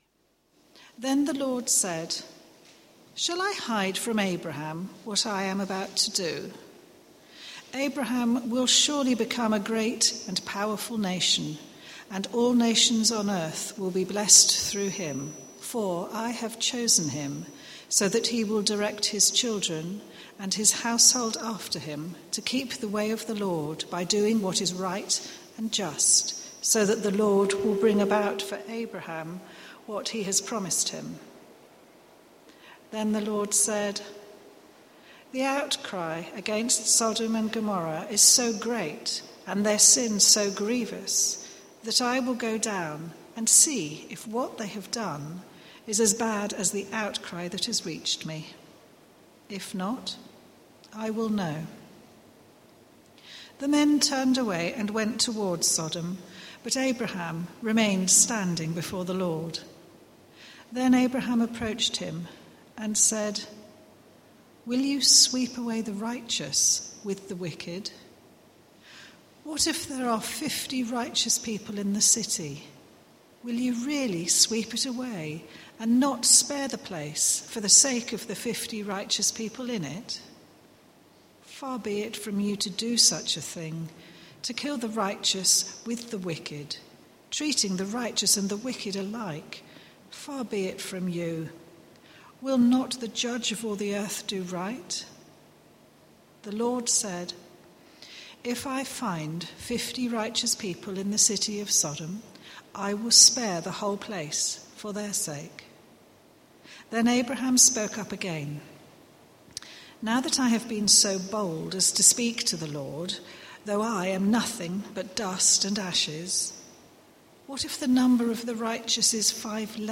Christ Church Morning Service
From small beginnings, Theme: How should we deal with God in prayer? Sermon All music is licensed by Podcast/RSS FEED The media library is also available as a feed, allow sermons to be automatically downloaded to your PC or smartphone.